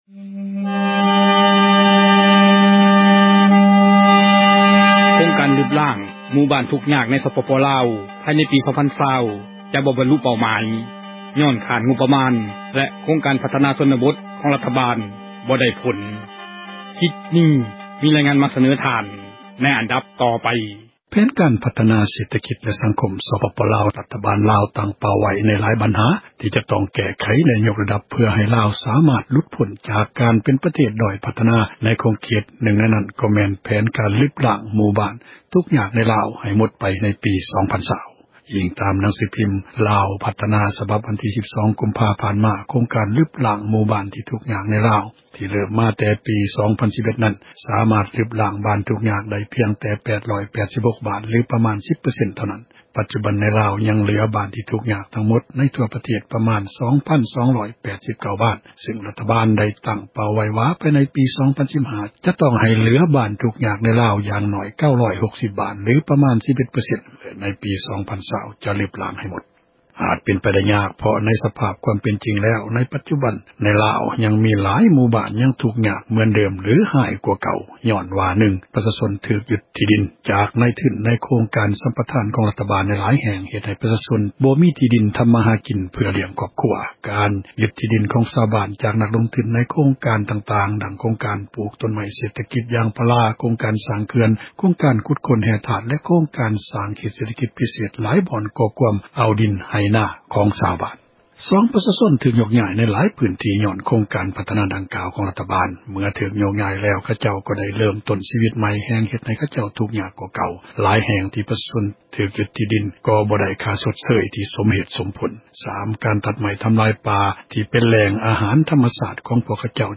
ປະຊາຊົນໃນໂຄງການບ້ານລຶບລ້າງຄວາມທຸກຍາກໃນລາວ ທ່ານນື່ງເວົ້າວ່າ ໃນຣະຍະ 2 ປີຜ່ານມາຫລັງຈາກບ້ານໄດ້ເຂົ້າຮ່ວມ ໂຄງການລຶບ ລ້າງຄວາມທຸກຍາກຂອງ ທາງການ ມີເຈົ້າຫນ້າທີ່ມາເຮັດໂຄງການພັທນາໂຄງຮ່າງພື້ນຖານຫລາຍ ໂຄງການ ແຕ່ສ່ວນຫລາຍດຳເນີນບໍ່ສຳເຣັດ ແລະບໍ່ສາມາດໃຊ້ການໄດ້.